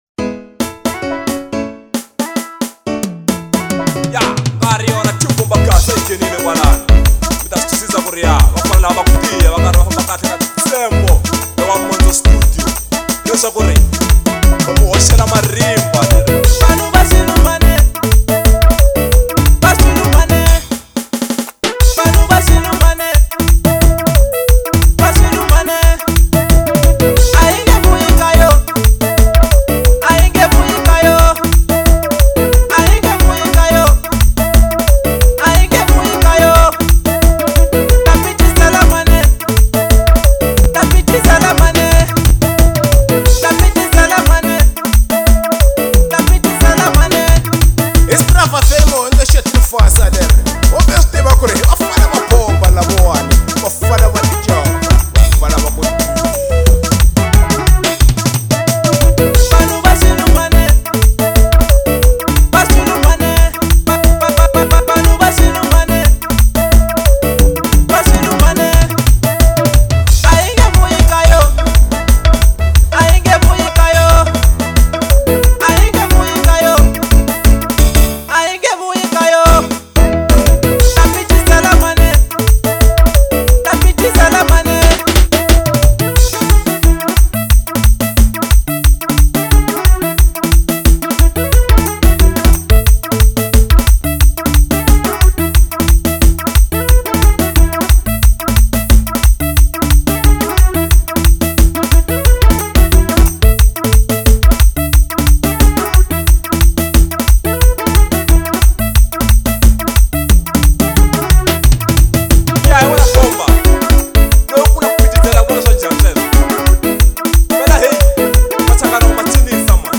04:44 Genre : Xitsonga Size